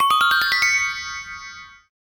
Magic_Wand.ogg